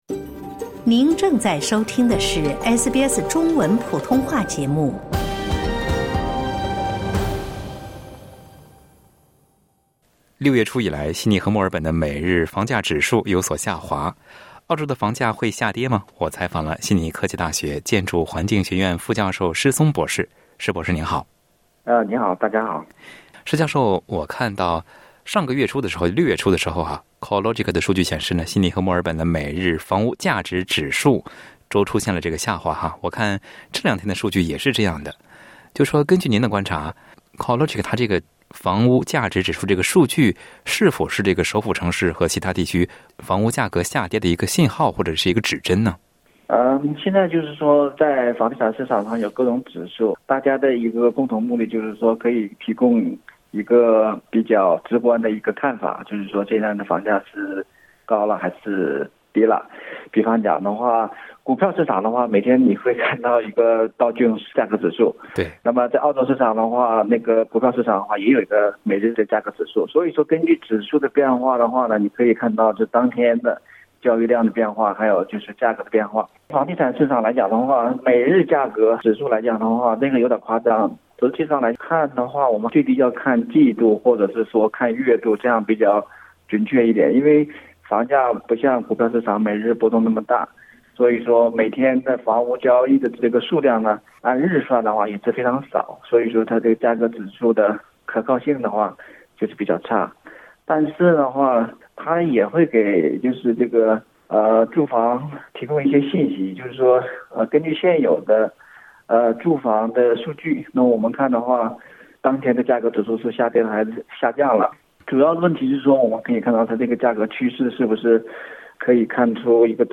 在接受SBS普通话节目采访时